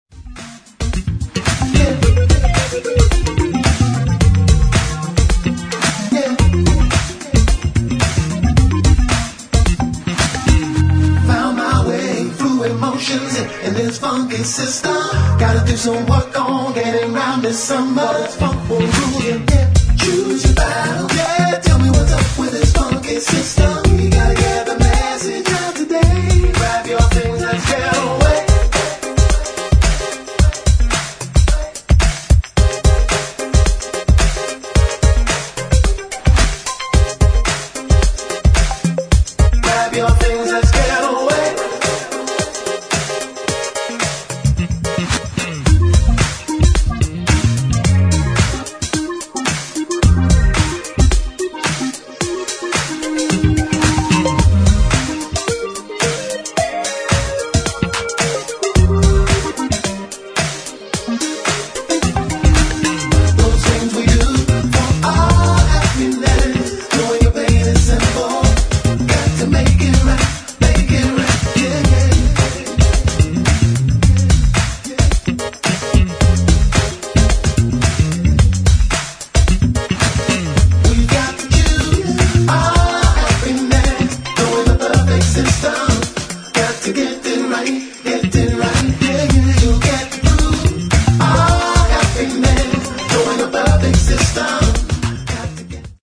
[ DISCO ]